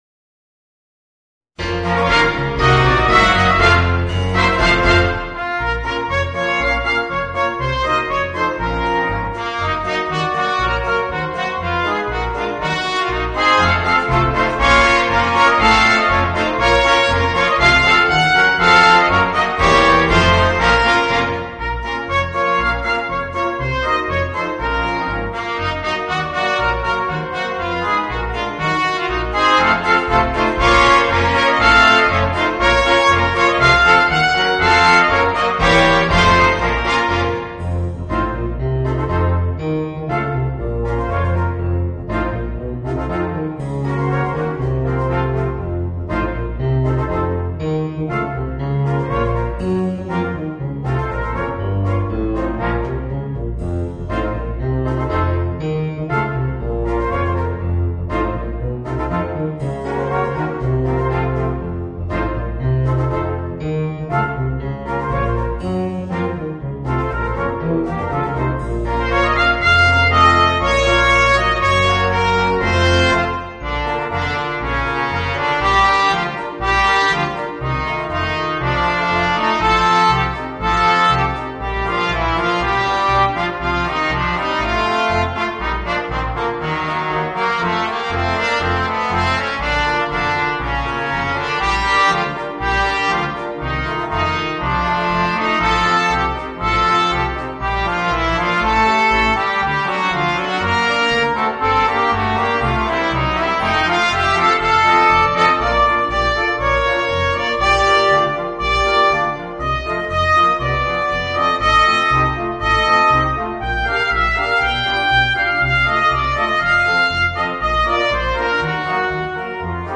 Voicing: 2 Trumpets, Horn, Trombone, Tuba and Piano